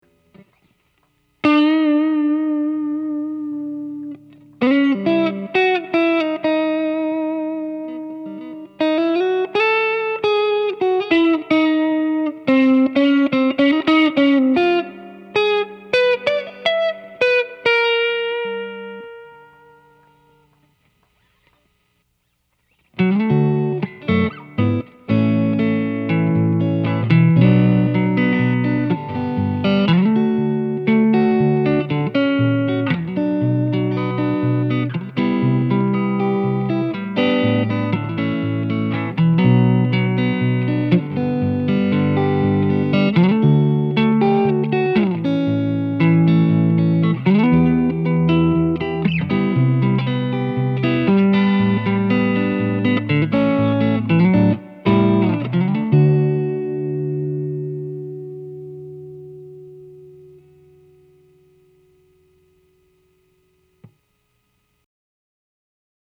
In the clip, I’m playing my Strat through a Hardwire reverb, into a Reason Bambino on the Normal channel, at just the edge of breakup. The tone that this produces is silky smooth, but responds to attack and volume increases with just bit of grind.
It creates a very three-dimensional sound.
The first part – thankfully – is very short, and is just random plucking of notes. Without touching anything on my guitar or amp, in the second part, I do a little chord comping and create some music.
tonetest.mp3